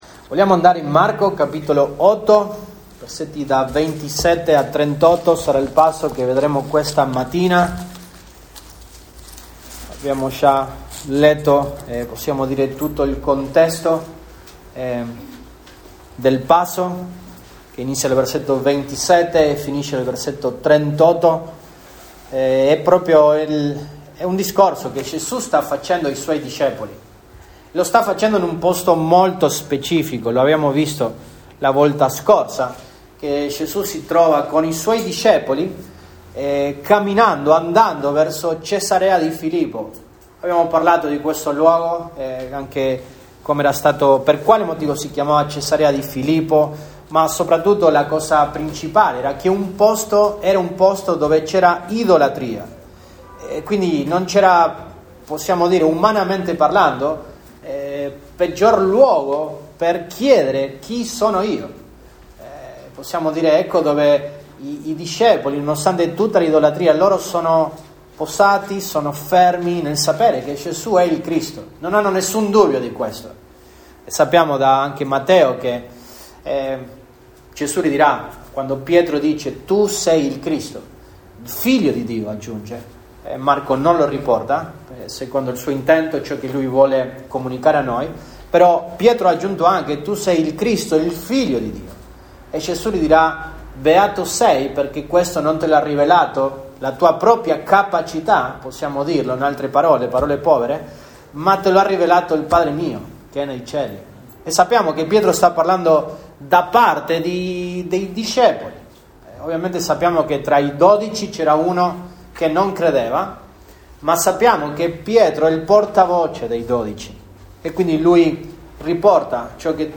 Dic 01, 2024 Gesù annuncia ai suoi discepoli le cose che li devono succedere MP3 Note Sermoni in questa serie Gesù annuncia ai suoi discepoli le cose che li devono succedere.